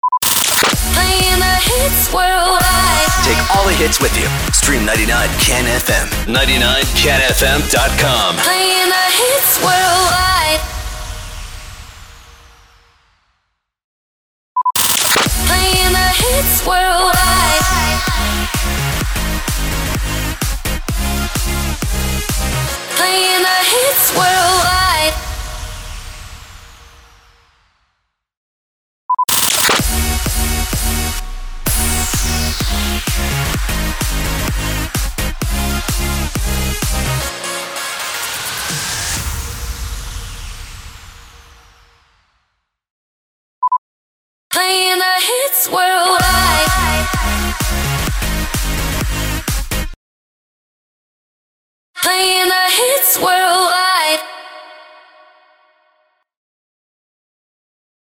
736 – SWEEPER – PLAYING THE HITS WORLDWIDE